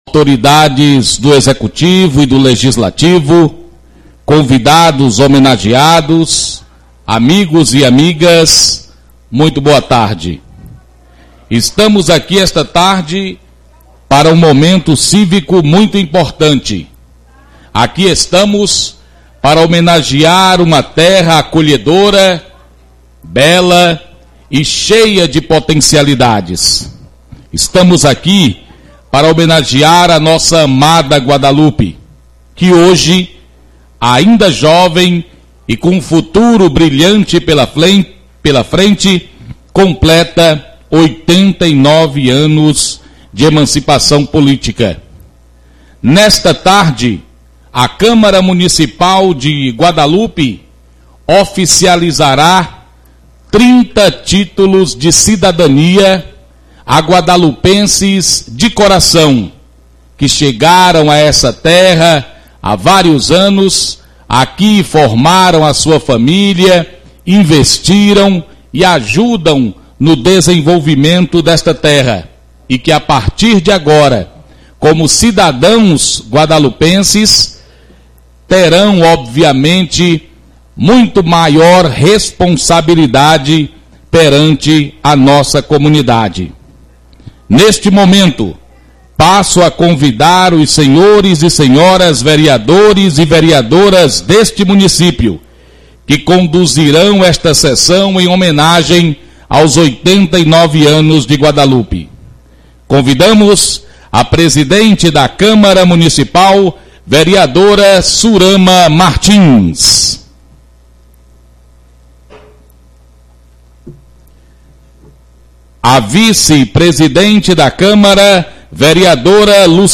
Áudio das Sessões do ano de 2018